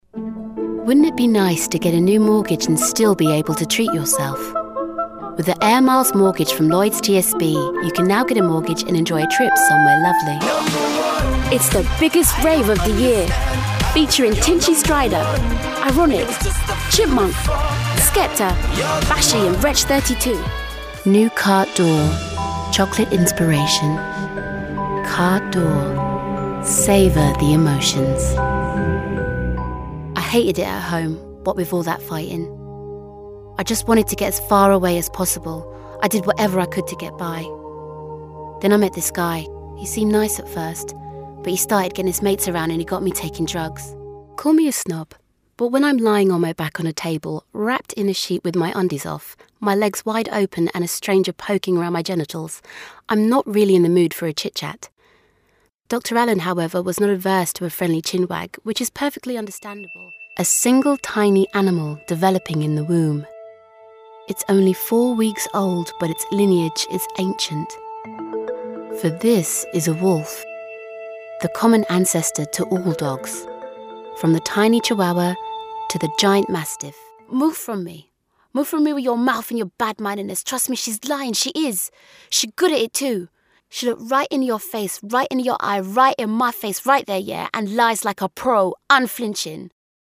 Voix off
15 - 35 ans - Mezzo-soprano